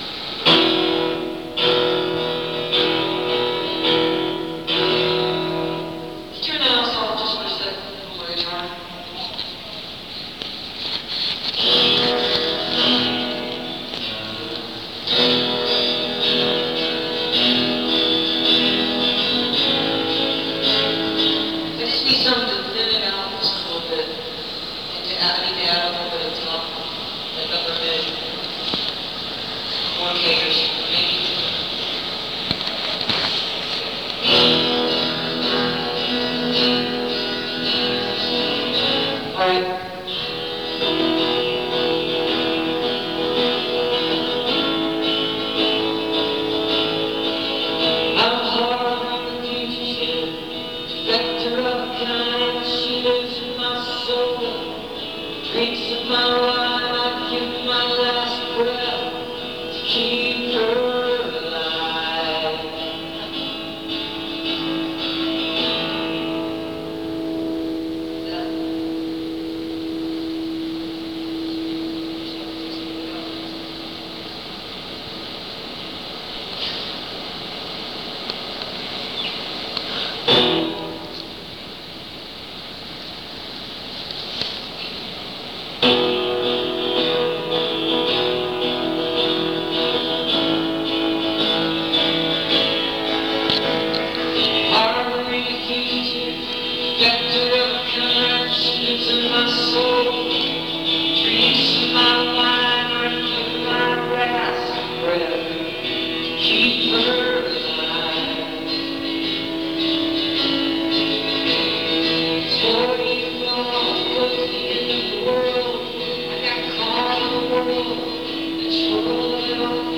(band show)
(soundcheck)